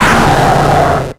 Cri de Granbull dans Pokémon X et Y.